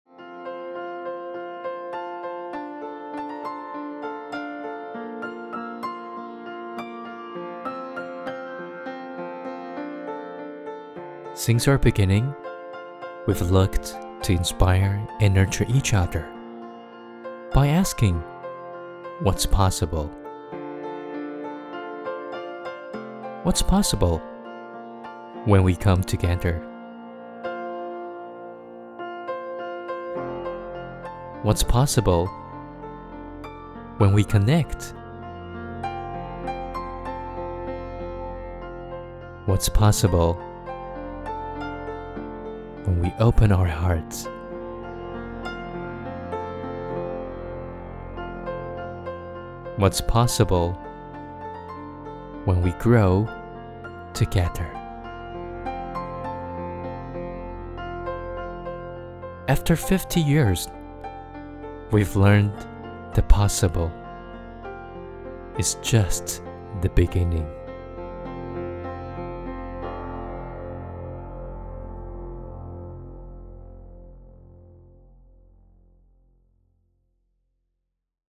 國語配音 男性配音員